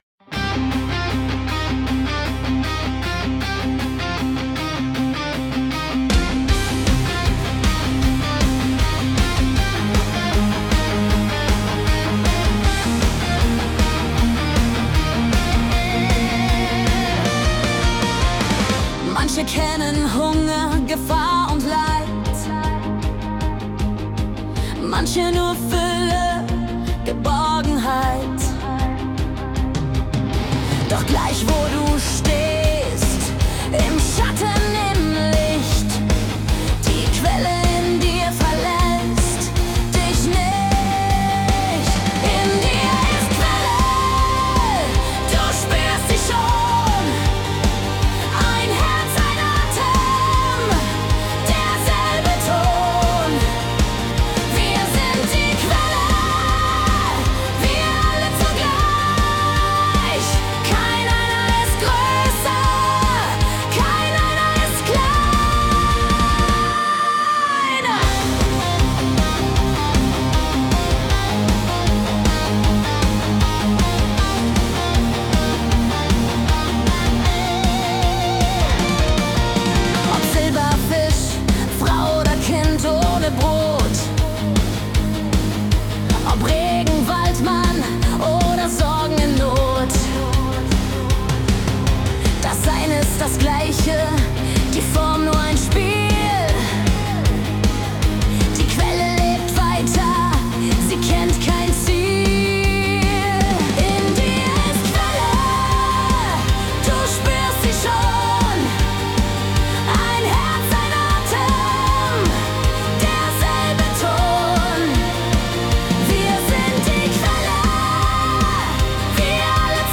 Melodic Heavy MetalBPM ~152